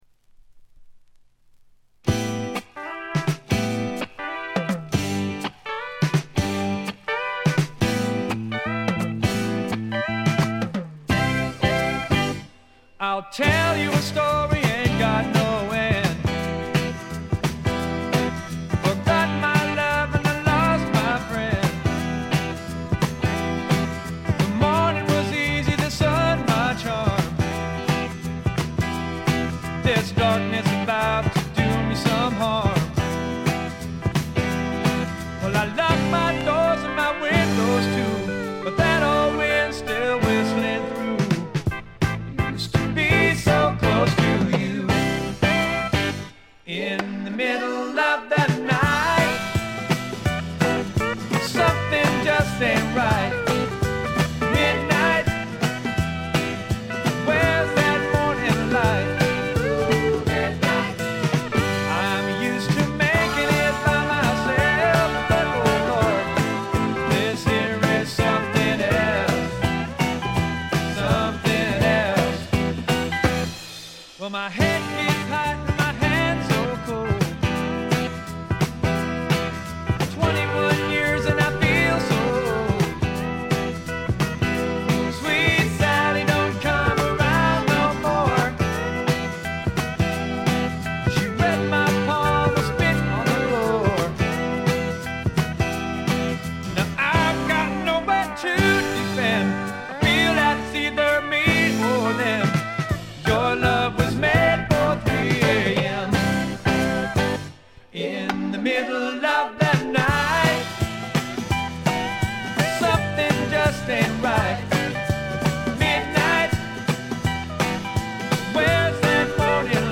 ほとんどノイズ感無し。
美しいコーラスが特に気持ち良いです。
試聴曲は現品からの取り込み音源です。
Recorded At - Kaye-Smith Studios